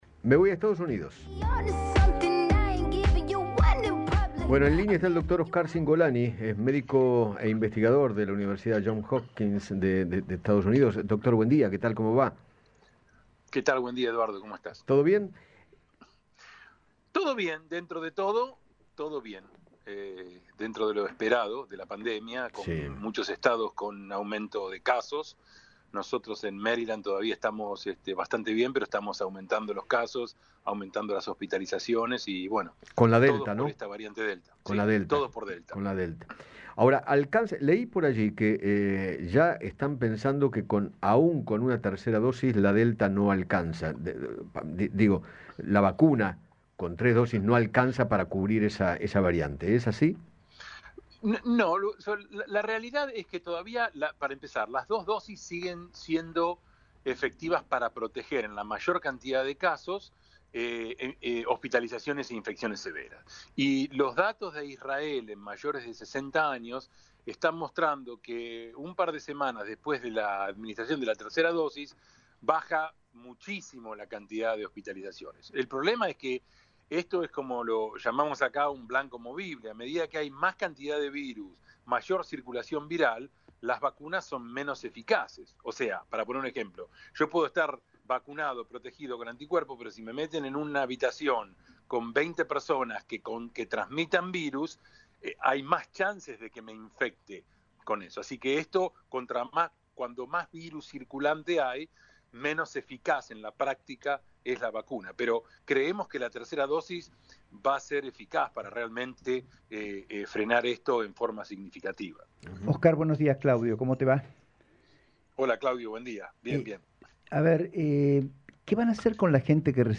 conversó con Eduardo Feinmann sobre el aumento de casos de covid en varios estados de Estados Unidos y sostuvo que “a medida que hay mayor circulación viral